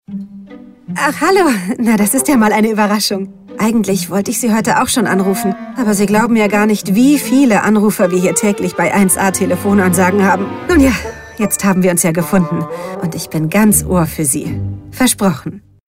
Kreative Telefonansage
Telefonansagen mit echten Stimmen – keine KI !!!
Beispiel 10. mit der deutschen Synchronstimme von Jennifer Lawrence